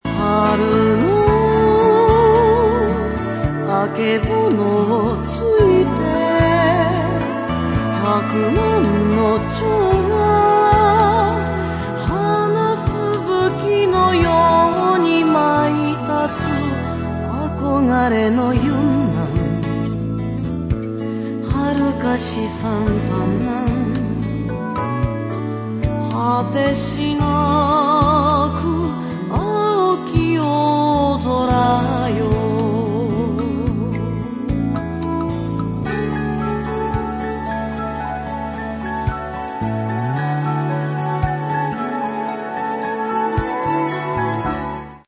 心持ちライトタッチな曲が集められたアルバム。